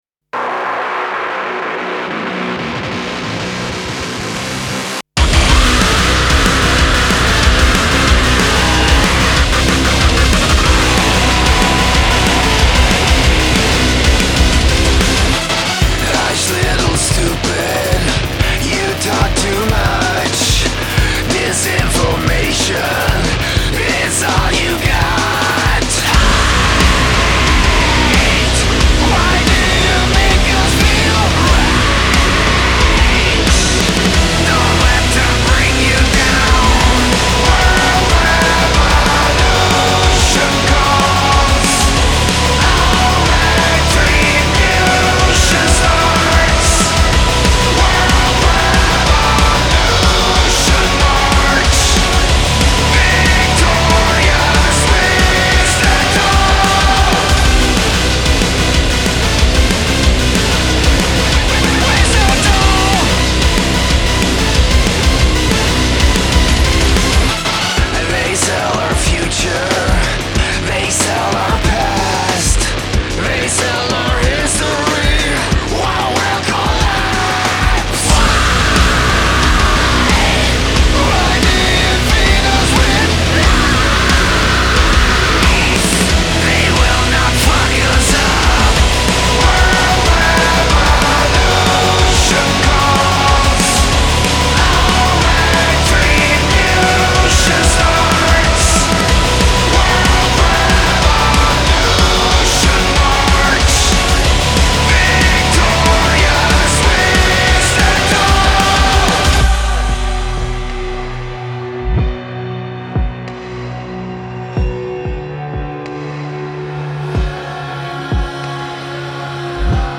بهترین آهنگ های سبک متال
موزیک آلترناتیو متال راک آلترناتیو